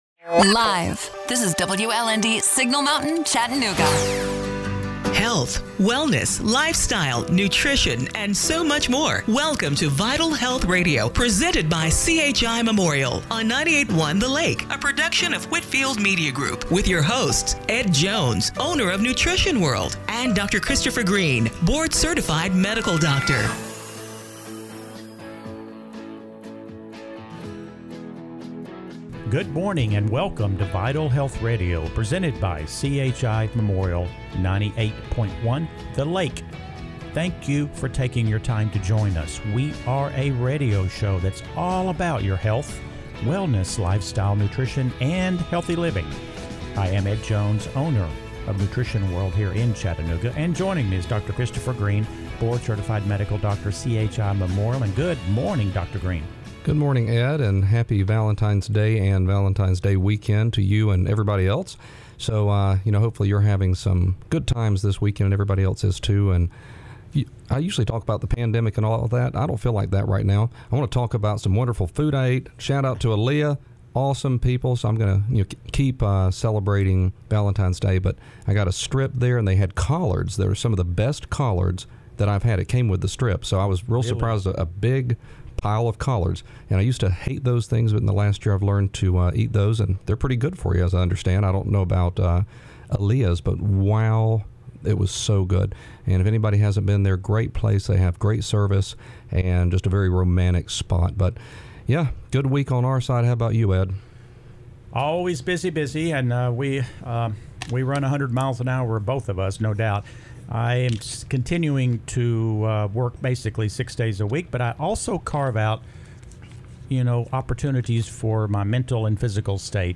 February 14, 2021 – Radio Show - Vital Health Radio